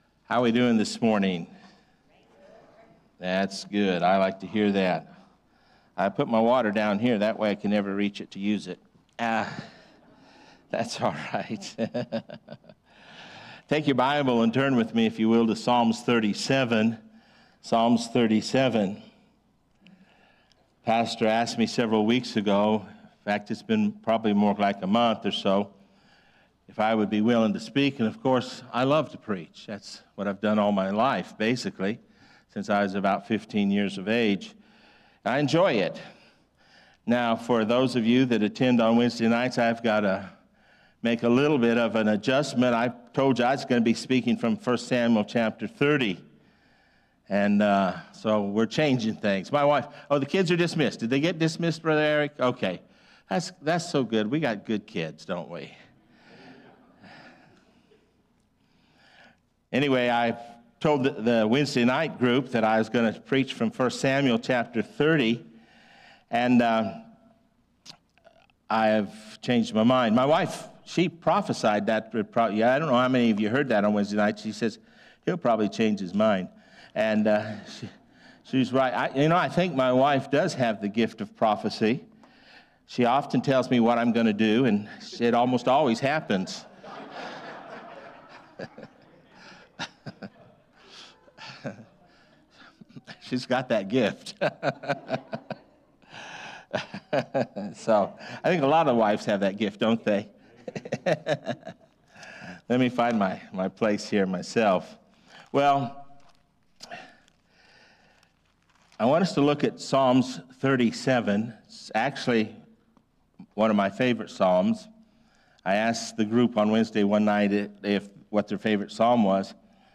A message from the series "Faith For A Reason."